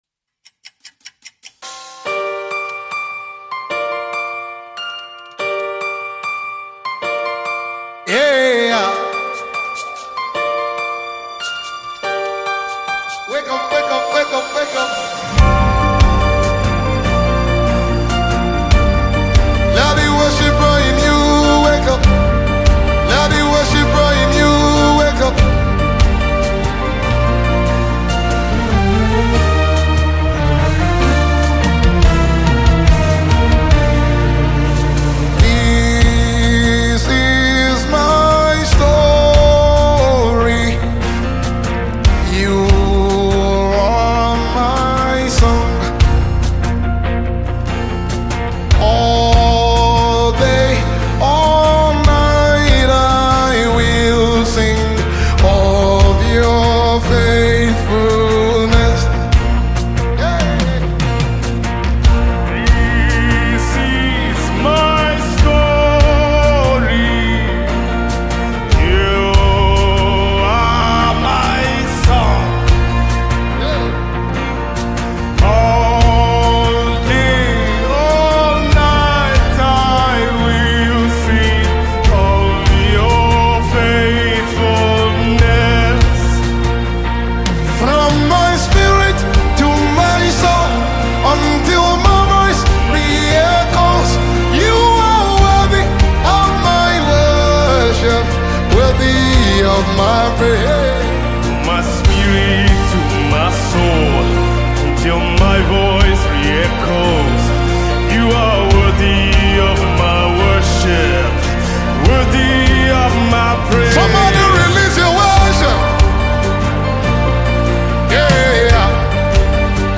soul-stirring anthem of praise and worship
With its uplifting message and captivating melody
Gospel/Christian music